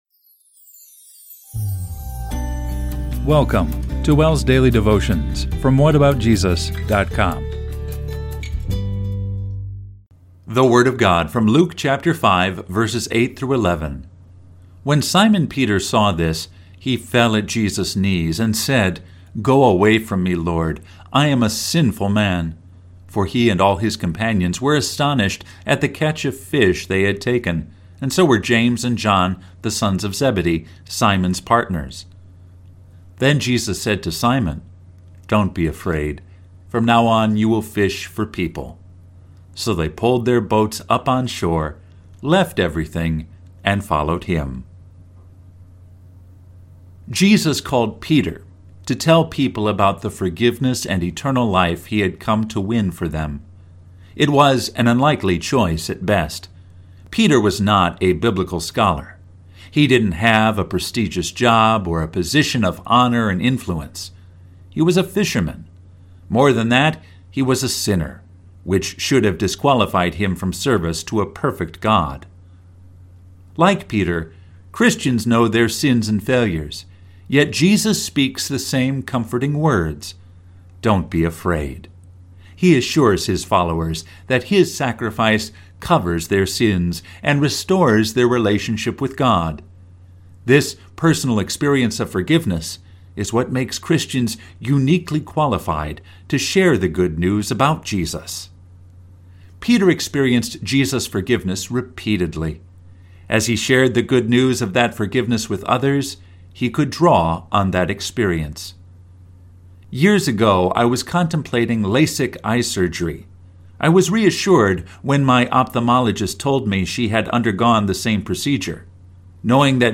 Devotion based on Luke 5:8-11